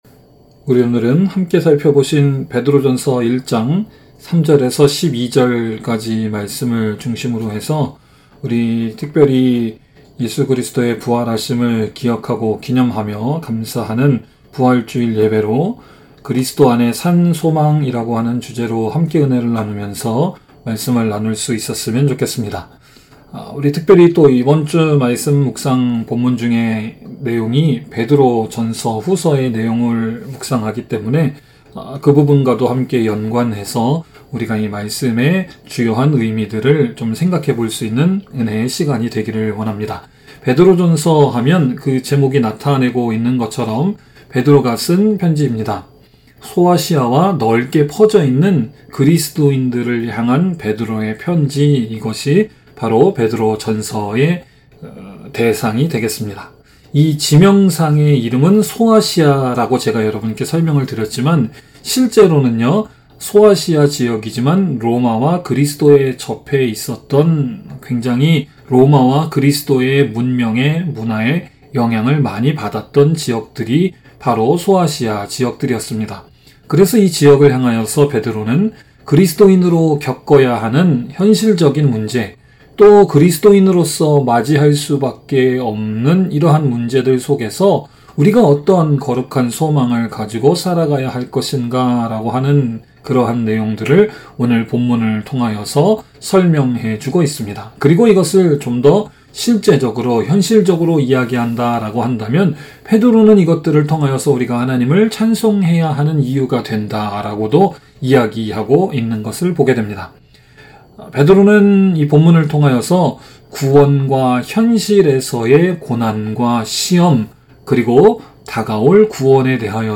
by admin | Apr 1, 2024 | 설교 | 0 comments